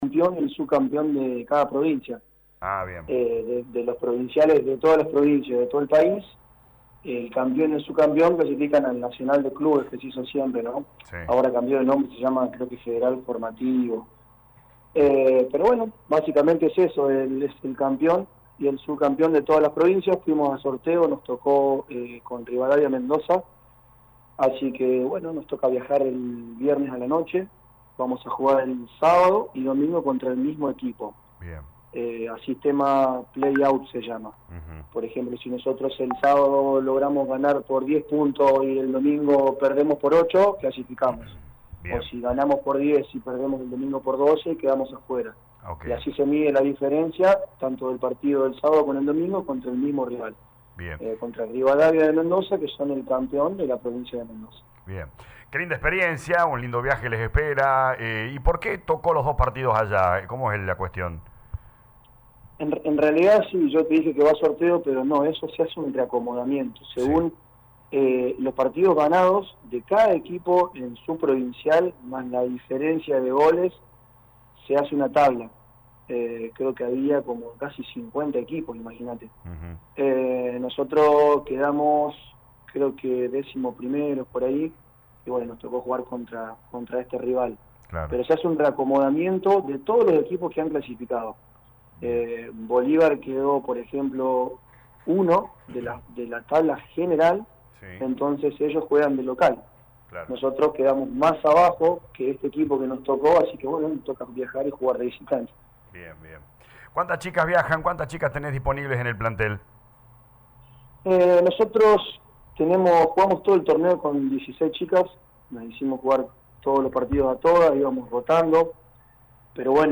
cuenta todos detalles del viaje en dialogo con LA MAÑANA D ELA RADIO.